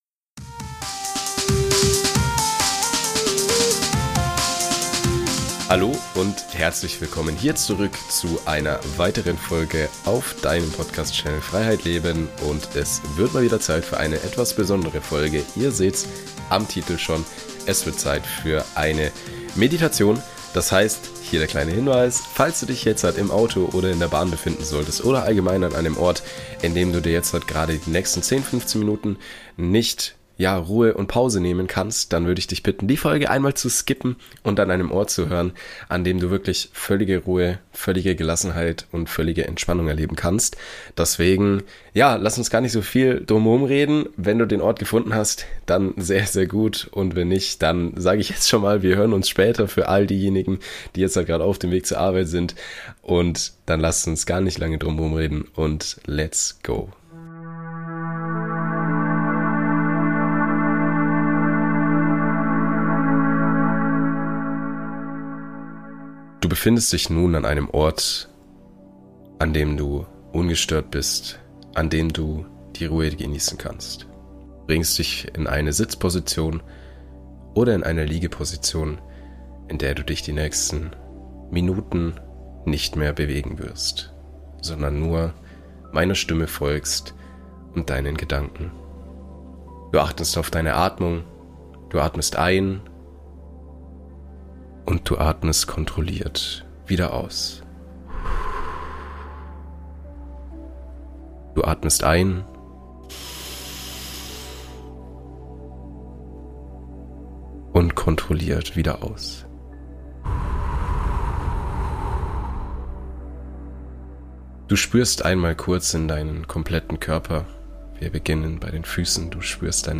Meditation zu einem besseren Ich, Thema Verantwortung